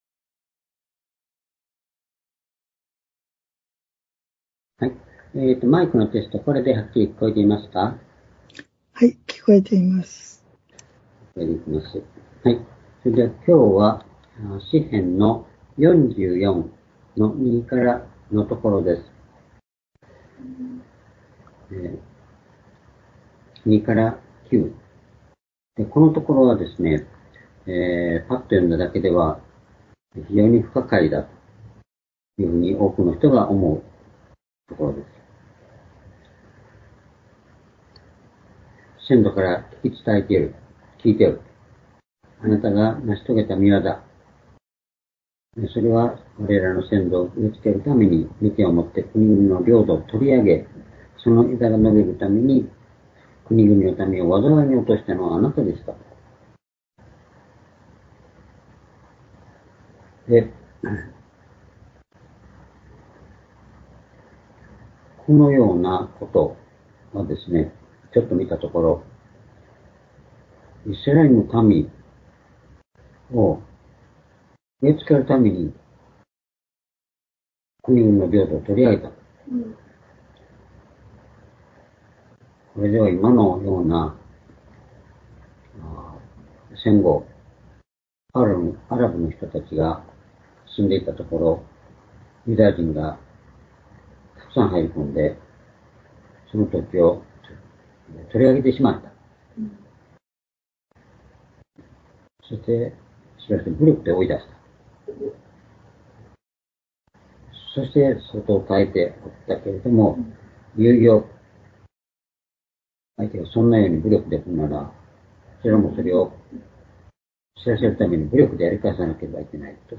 （主日・夕拝）礼拝日時 ２０２４年８月６日（夕拝） 聖書講話箇所 「弓や剣でなく、主によって」 詩編44編2節から9節 ※視聴できない場合は をクリックしてください。